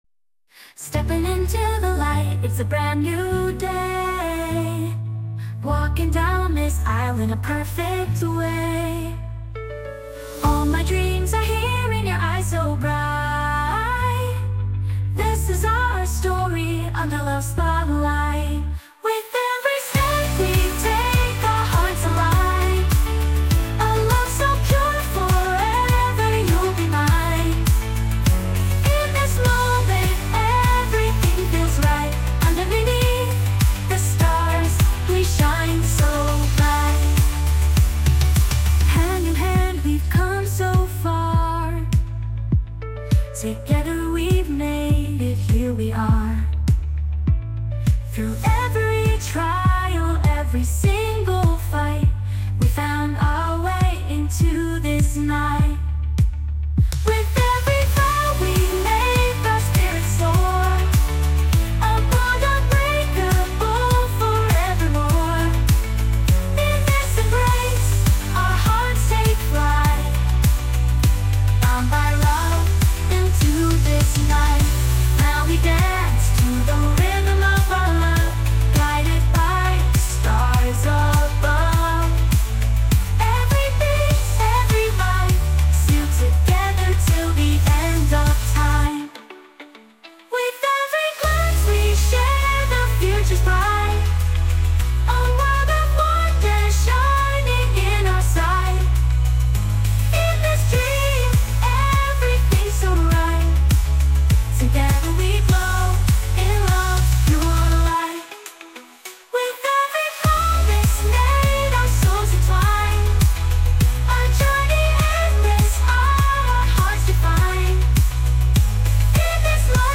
洋楽女性ボーカル著作権フリーBGM ボーカル
著作権フリーのオリジナルBGMです。
女性ボーカル曲（英語・洋楽）です。